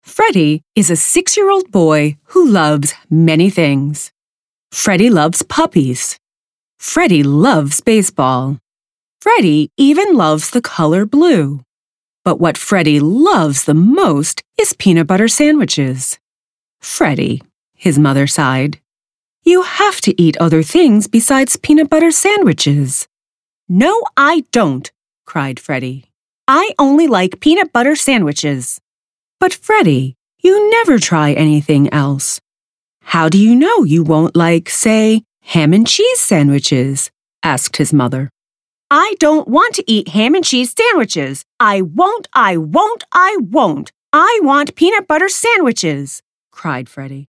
Precise, articulate delivery.
- Calm, credible, and grounded vocal presence
- Treated home booth